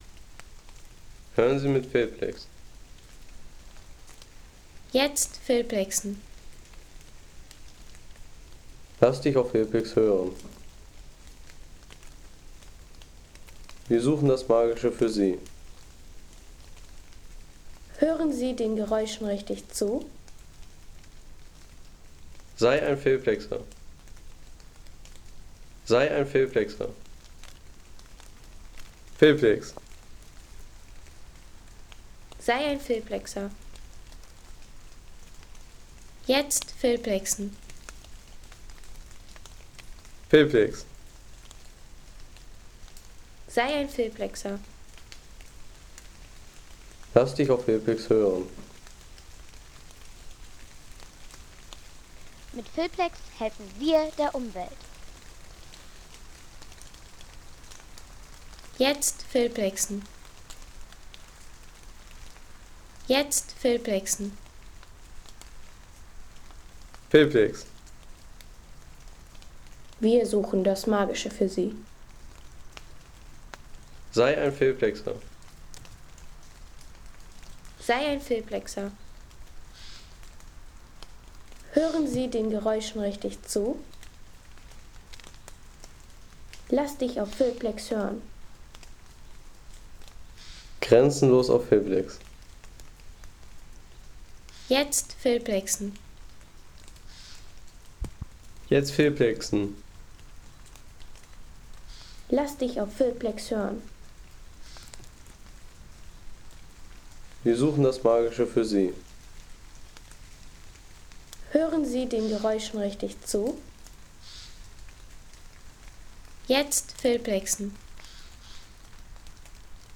Regen im Laubwald
Regenstimmung im Jesteburger Laubwald.